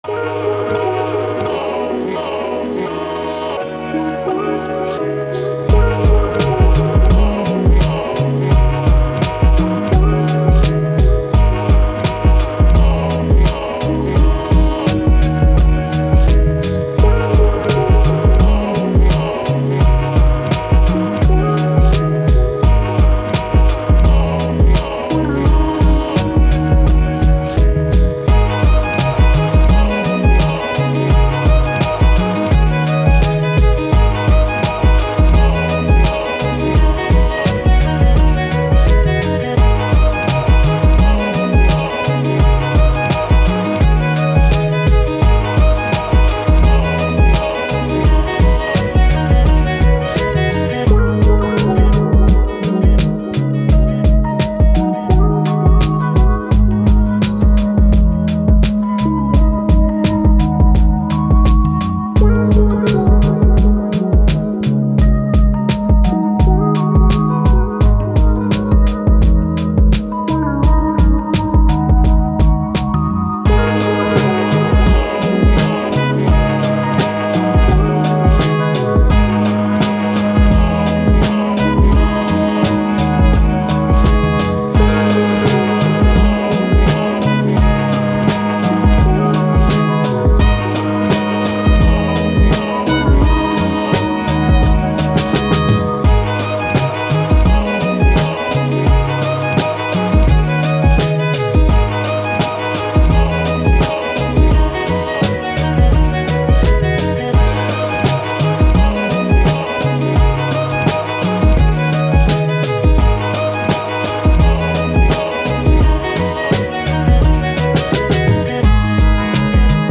Hip Hop, Boom Bap, Action